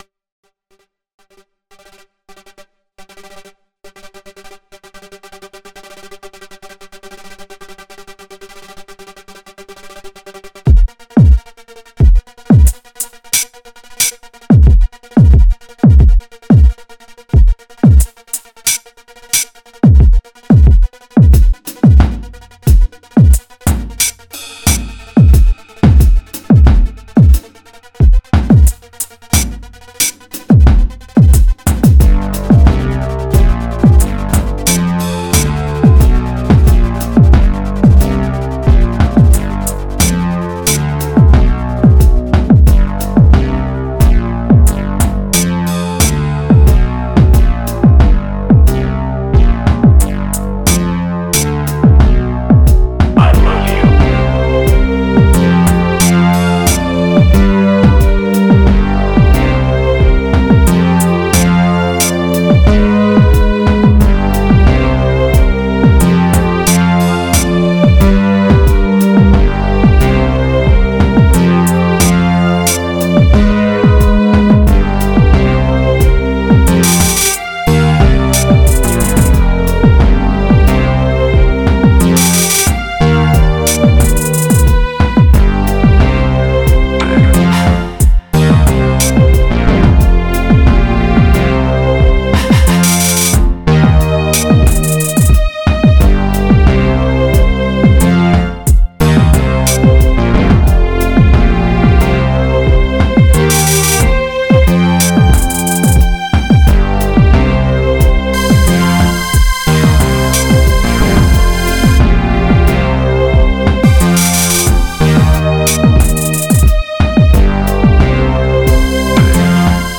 Categorised in: dance, dark, rhythmic